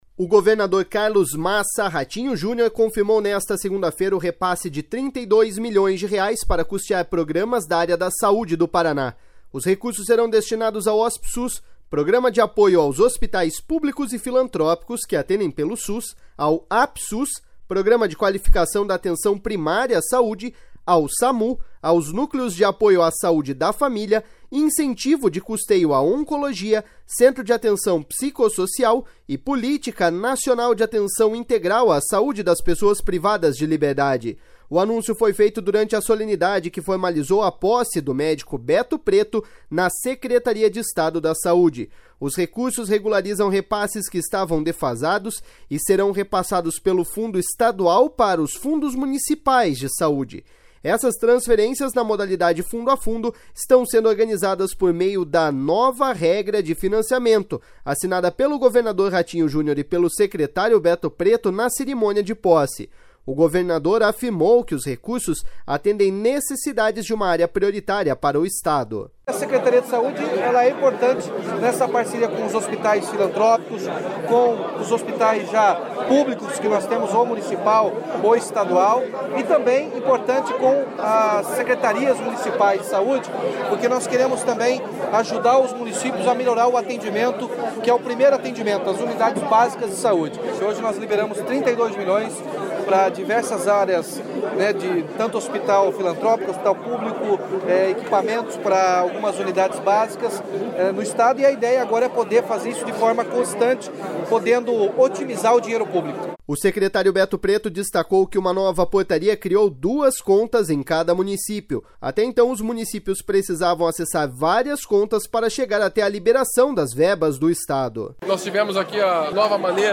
O governador afirmou que os recursos atendem necessidades de uma área prioritária para o Estado.// SONORA RATINHO JUNIOR.//
O secretário Beto Preto destacou que uma nova portaria criou duas contas em cada município. Até então os municípios precisavam acessar várias contas para chegar até a liberação das verbas do Estado.// SONORA BETO PRETO.//